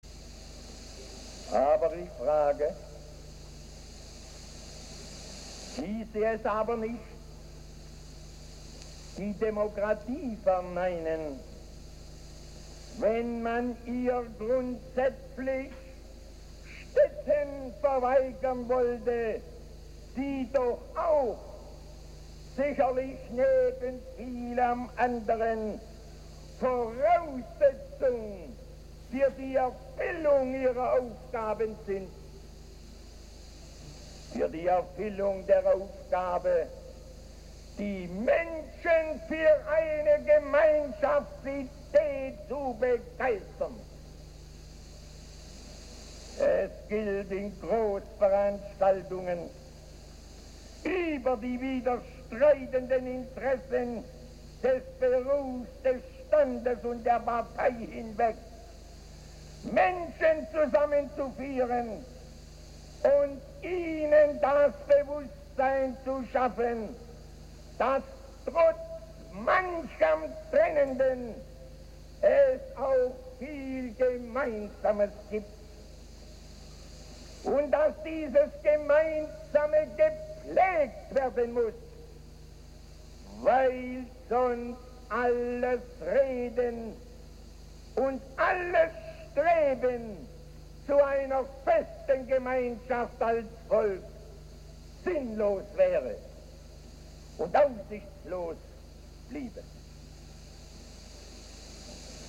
Informationen aus dem Stadtarchiv der Stadt Dortmund zur Rede des Dortmunder Oberbürgermeisters F. Henßler zur Eröffnung der neuen Westfalenhalle
Ausschnitt aus einer Rede des Dortmunder Oberbürgermeisters Fritz Henßler anlässlich der Eröffnung der neuen Westfalenhalle am 02. Februar 1952
redeausschnitt_fritz_henssler_1952.mp3